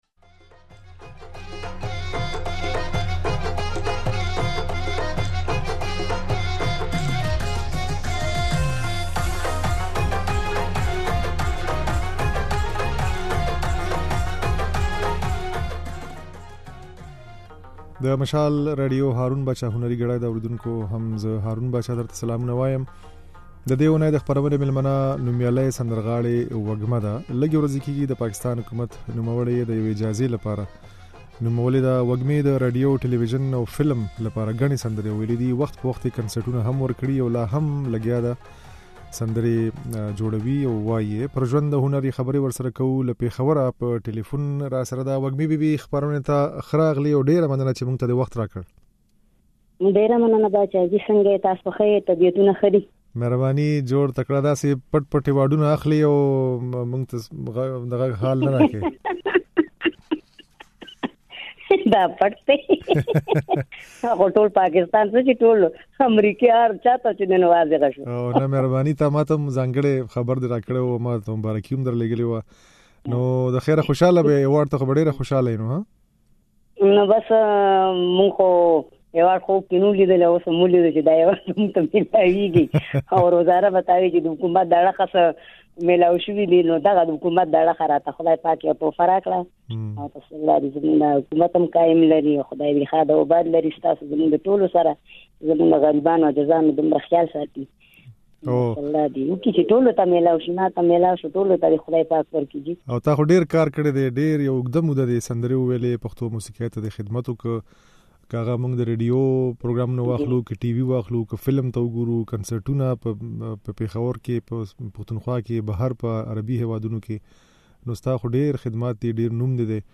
د دې اوونې د "هارون باچا هنري ګړۍ" خپرونې مېلمنه نوميالۍ سندرغاړې وږمه ده.